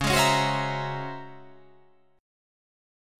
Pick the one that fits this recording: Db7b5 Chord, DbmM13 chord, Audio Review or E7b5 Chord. DbmM13 chord